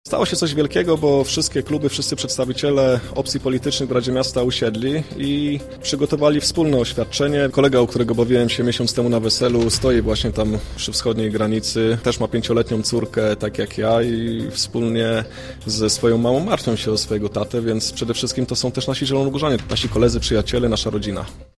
Podczas nadzwyczajnej sesji Rady Miasta Zielona Góra zostało dziś przyjęte oświadczenie w sprawie wsparcia zielonogórzan – żołnierzy, funkcjonariuszy Straży Granicznej i Policji – strzegących wschodniej granicy Polski.